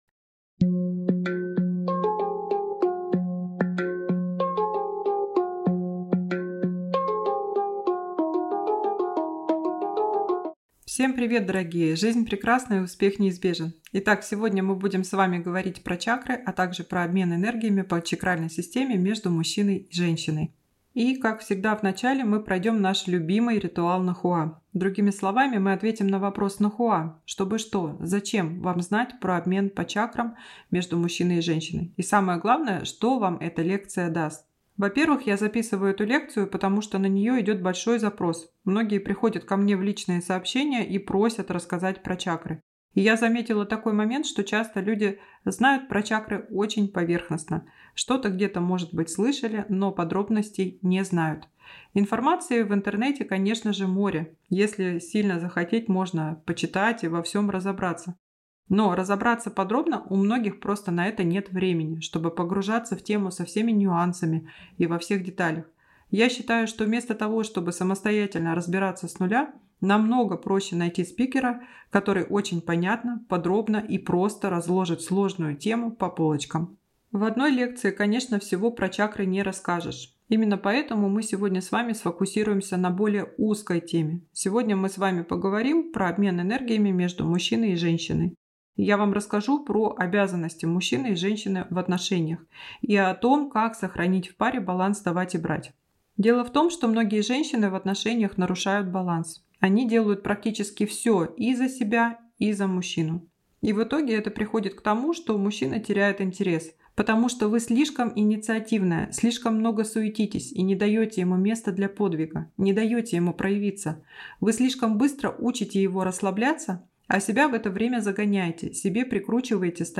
Аудиокнига Чакры, энергообмен между мужчиной и женщиной. Лекция 1. Муладхара | Библиотека аудиокниг